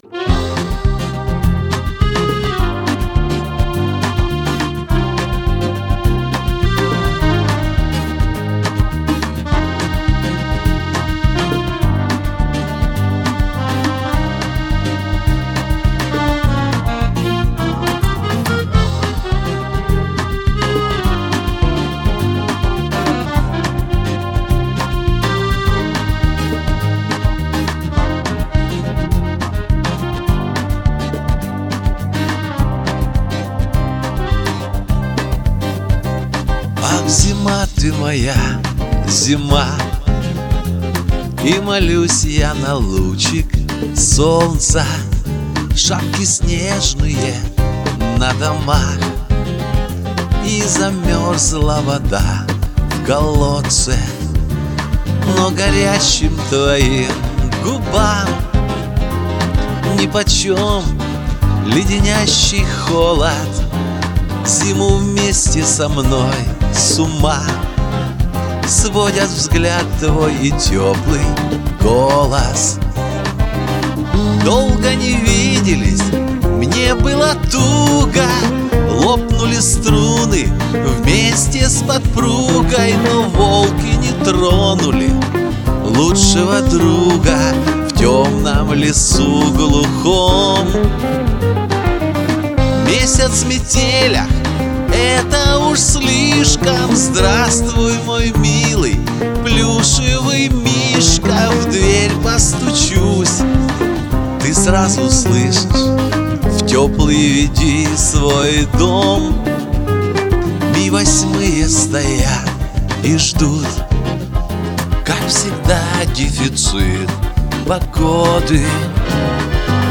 А меня радуют ваши настоящие живые голоса!